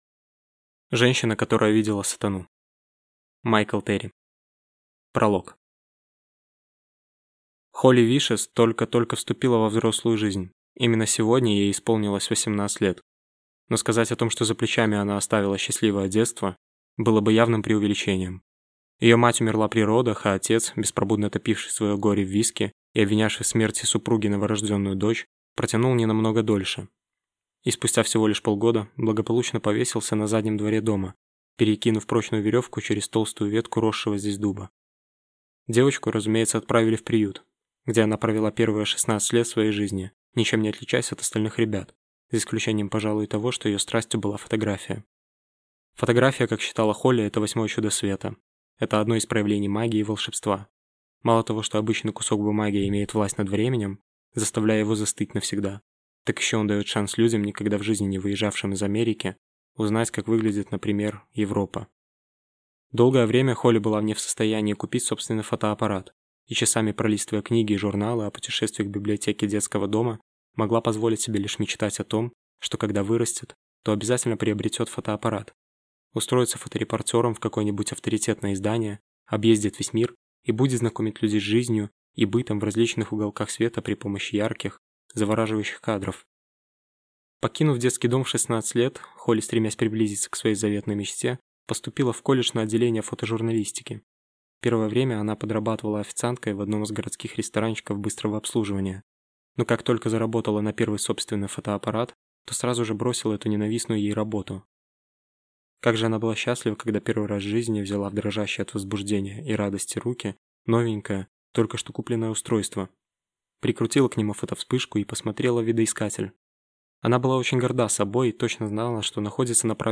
Аудиокнига Женщина, которая видела Сатану | Библиотека аудиокниг